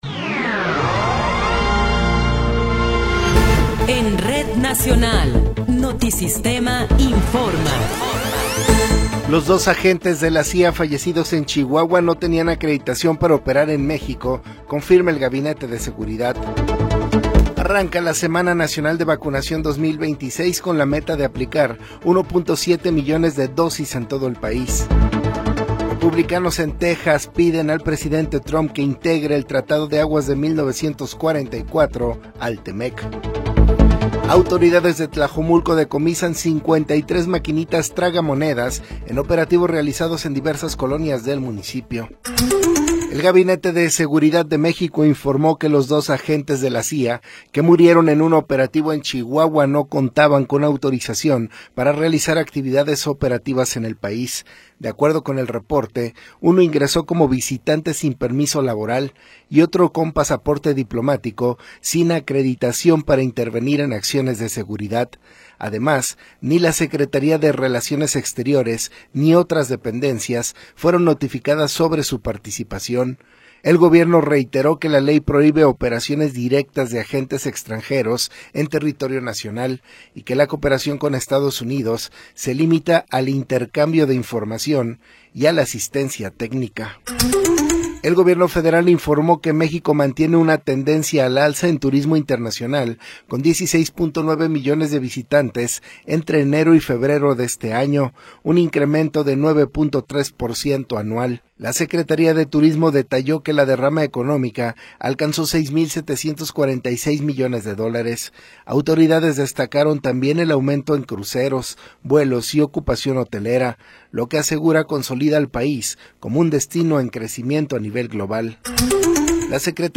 Noticiero 20 hrs. – 25 de Abril de 2026